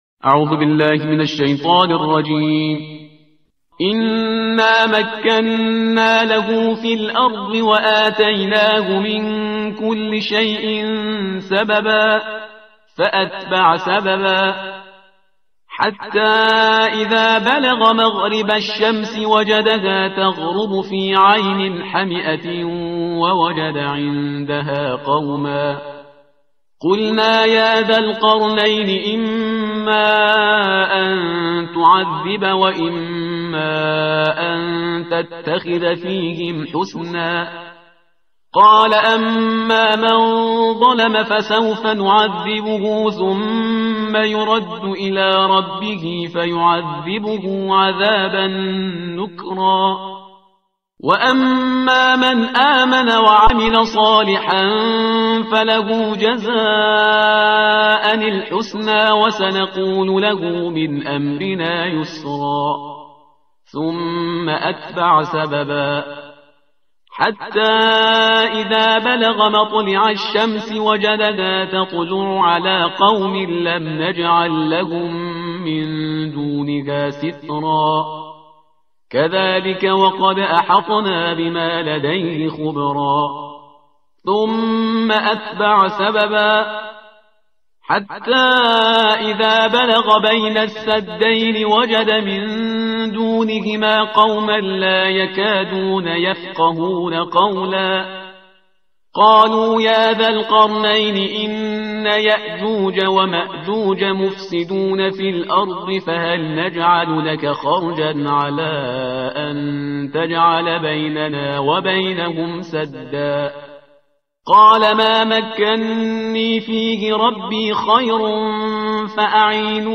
ترتیل صفحه 303 قرآن با صدای شهریار پرهیزگار